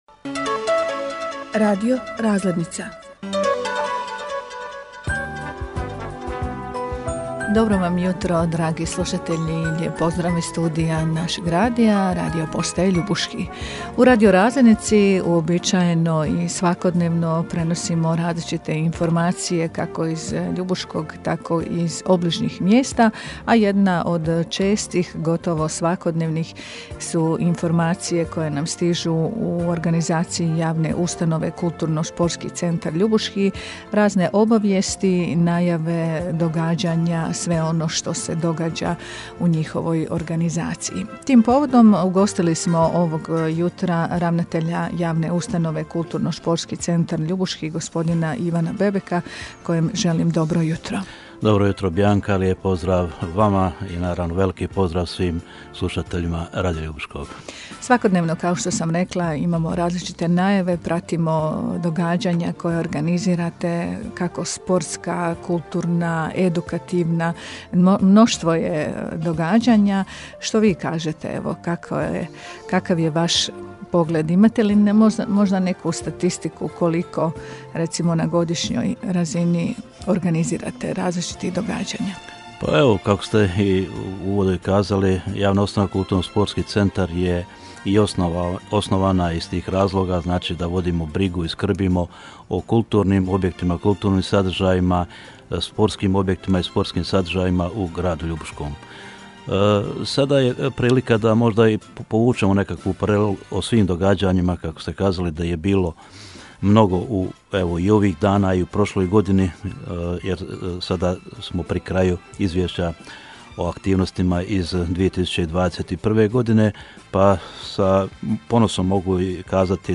u programu Radija Ljubuški